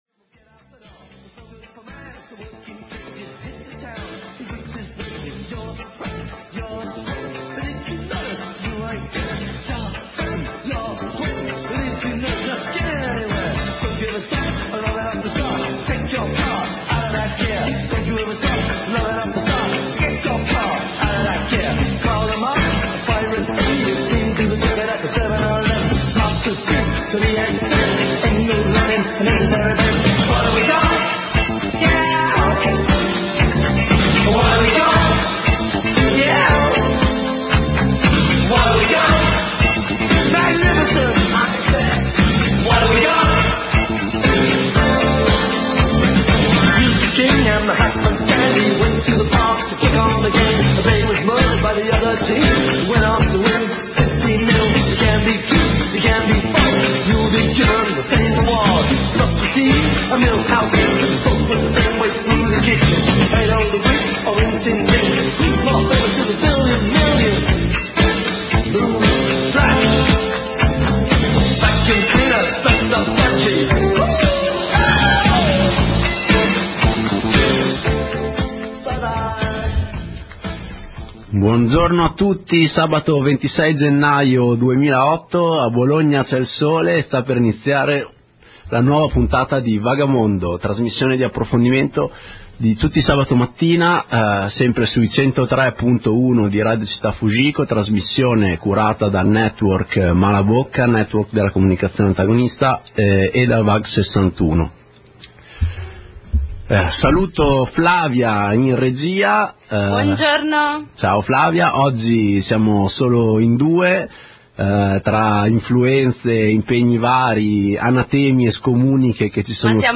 Il mondo politico reagisce gridando allo scandalo per l'annulata partecipazione in seguito alle contestazioni studentesche: rassegna stampa e inquietanti audio da rariomaria e tg2.